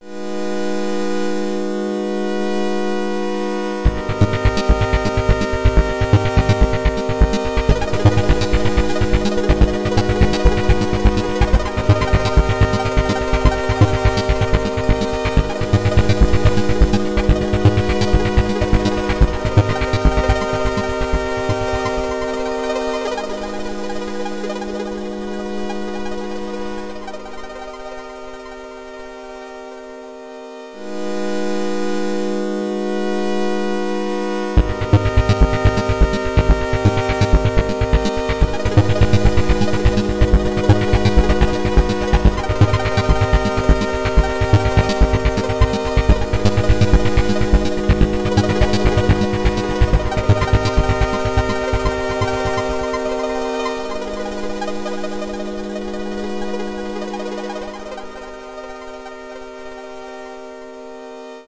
- added "waveguide idx" sample parameter. this can be used to "morph"/blend between arbitrary cycles of a wavetable.